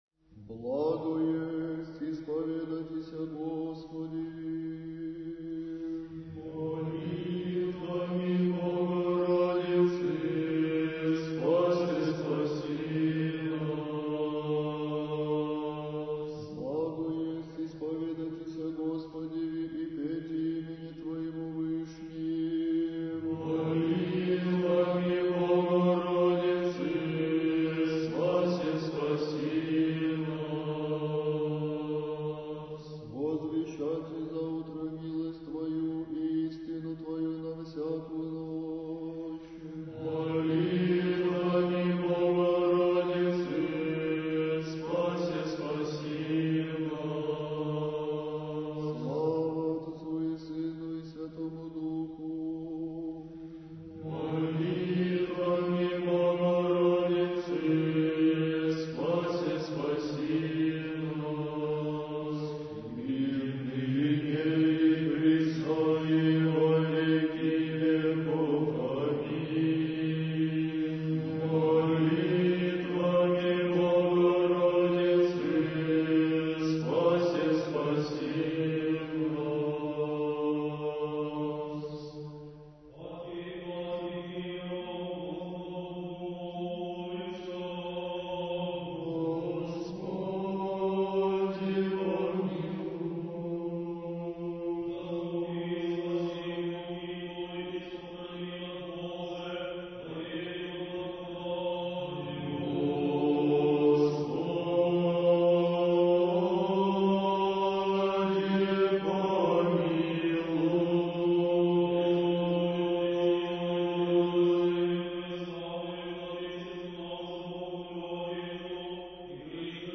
Духовная музыка / Русская